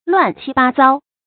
亂七八遭 注音： ㄌㄨㄢˋ ㄑㄧ ㄅㄚ ㄗㄠ 讀音讀法： 意思解釋： 見「亂七八糟」。